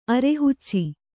♪ arehucci